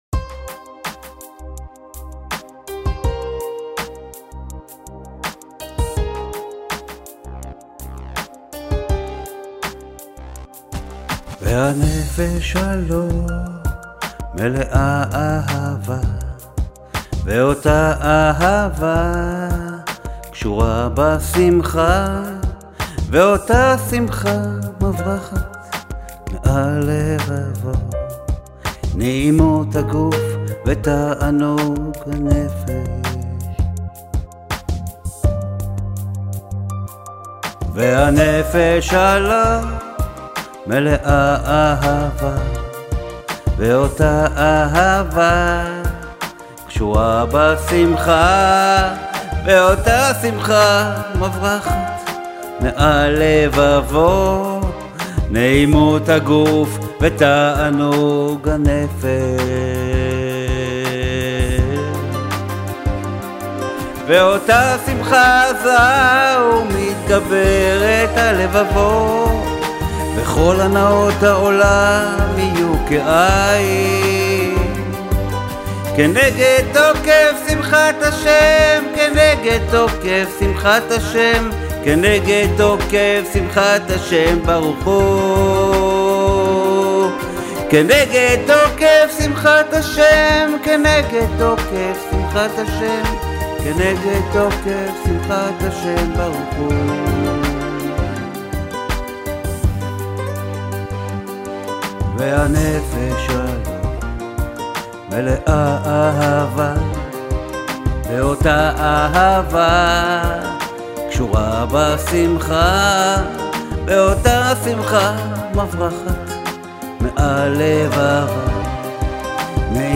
באוזניות זה נשמע יותר טוב בהרבה